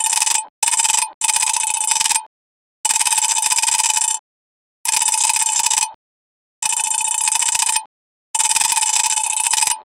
get card from deck 0:15 Video game coin being collected similar to Super Mario. 0:15 Coin collect sound effect to 2d game 0:03 Sound effect for a started QTE, look like the sound for Skill Checks are a Game Mechanic of Quick Time Events (QTE) in Dead by Daylight, In order to succeed a Skill Check, Survivors must press the Secondary Action button once the pointer is inside the highlighted area, also called the Success Zone. 0:10
sound-effect-for-a-starte-uzfvxb3k.wav